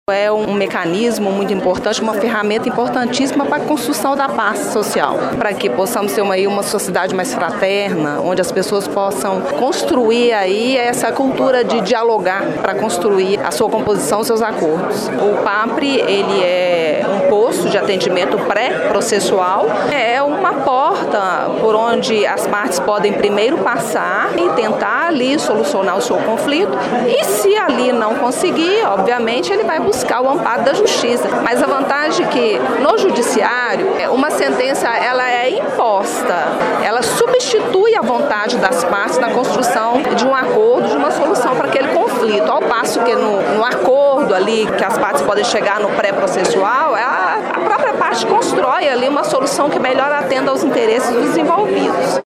Já a coordenadora do CEJUSC, Juíza Silmara Silva Barcelos, chamou atenção para os acordos que serão possíveis a partir do PAPRE, favorecendo um sentimento de paz social.